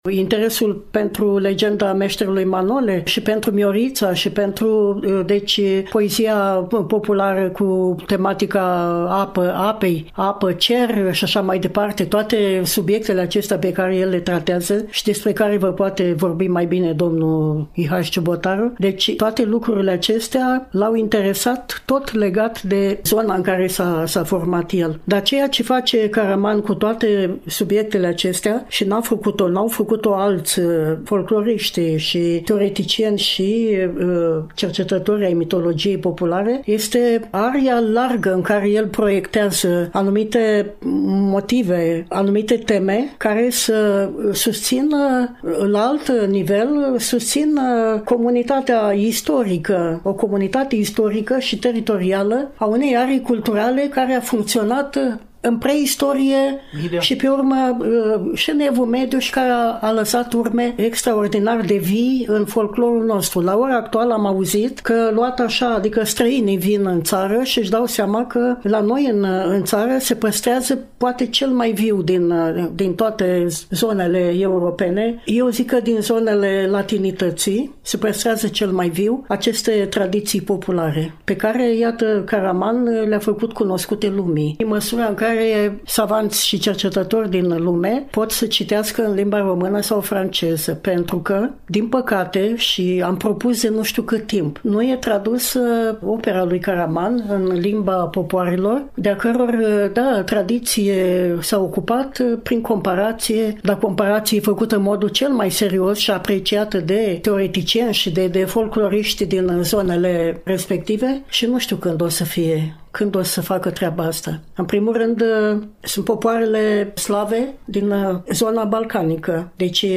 vorbește cu durere, dar și cu revoltă, în suflet